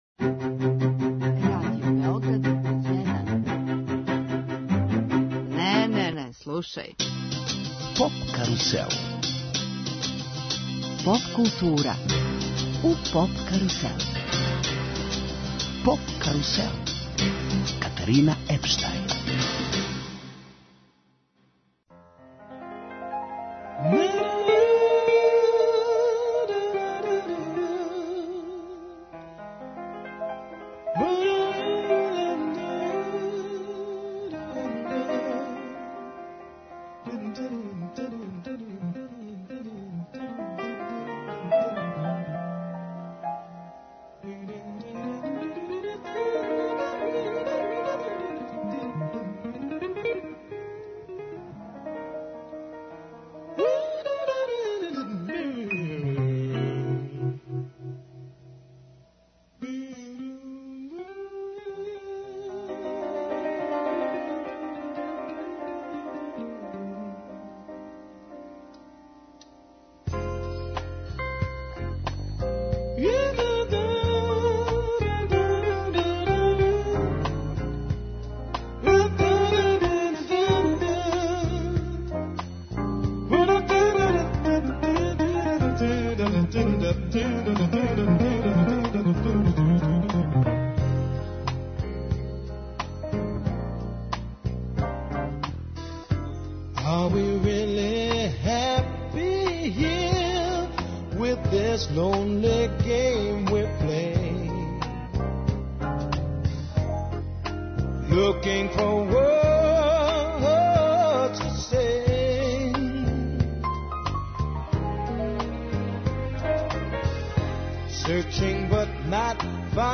Гост емисије је музички уметник Божо Врећо, поводом концерта који ће одржати у Београду.